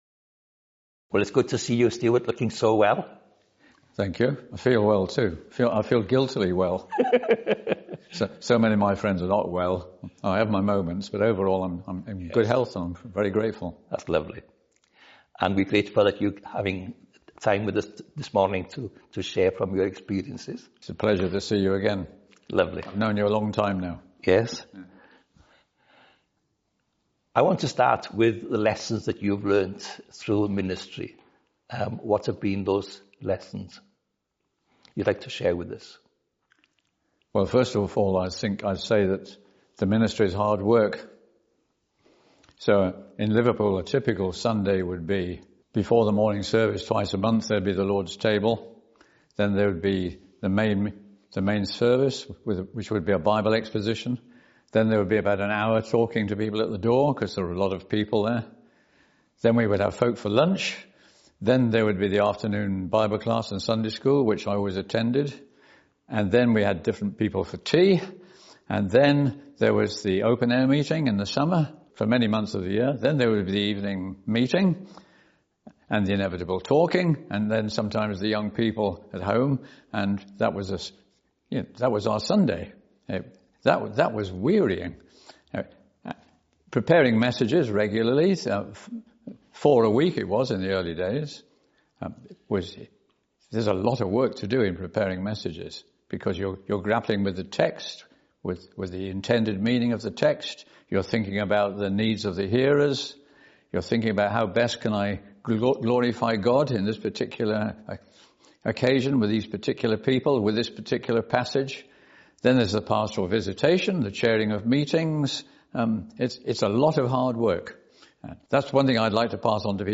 A Modern Day Barnabas | In Conversation